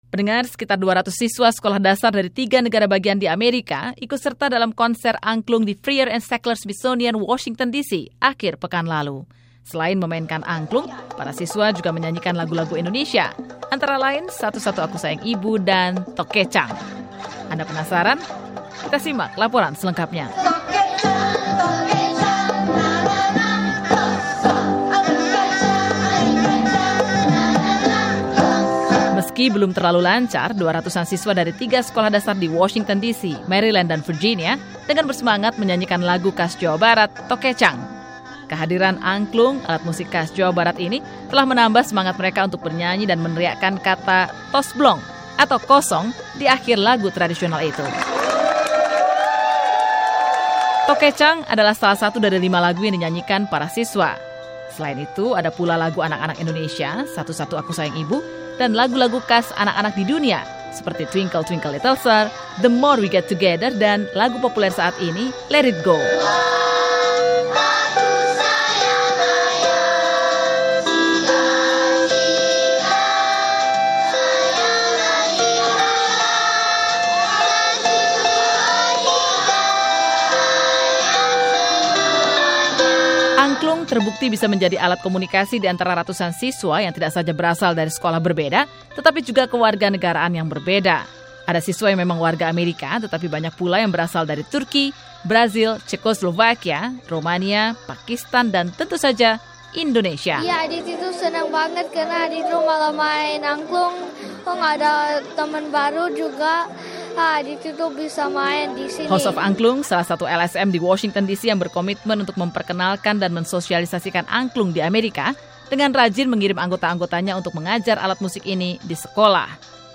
Sekitar 200 siswa sekolah dasar dari tiga negara bagian AS ikut serta dalam konser angklung dalam pertunjukan di Washington DC hari Minggu (5/10).